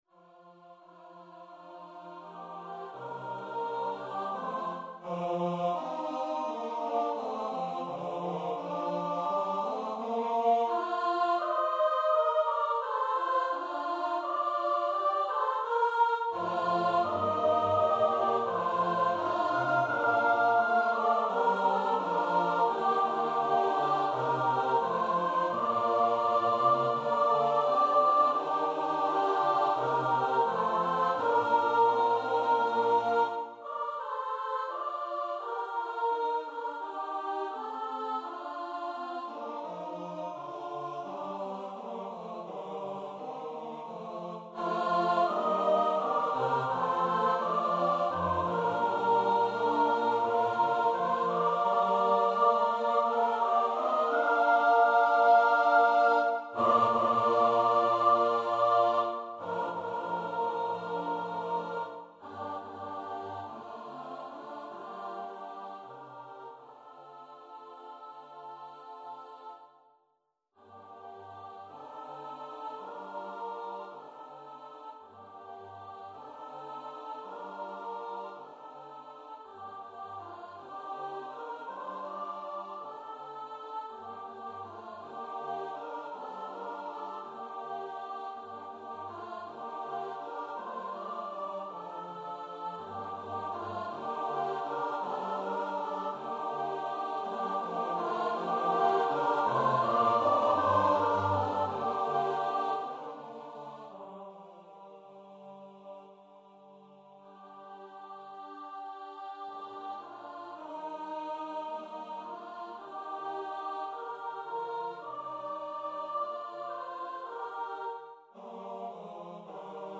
a cappella SATB choir